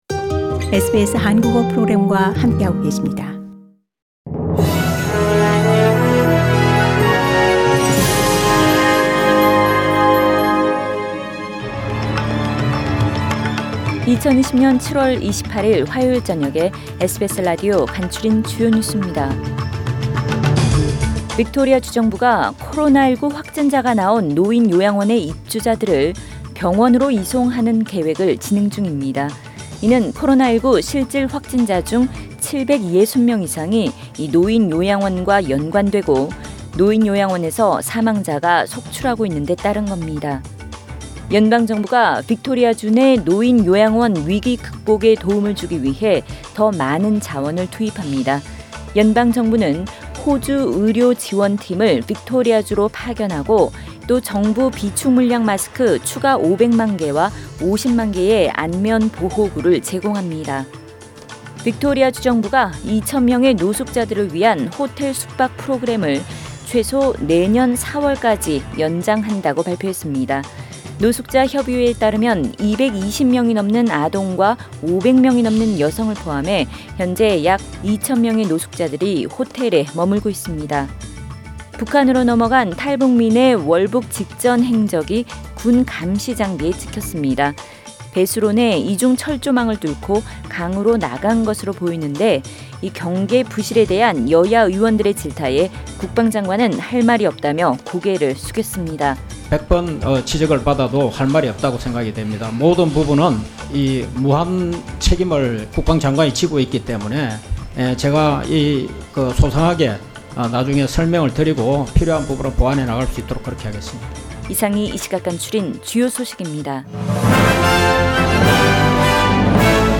SBS 한국어 뉴스 간추린 주요 소식 – 7월 28일 화요일
2020년 7월 28일 화요일 저녁의 SBS Radio 한국어 뉴스 간추린 주요 소식을 팟 캐스트를 통해 접하시기 바랍니다.